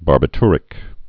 (bärbĭ-trĭk, -tyr-)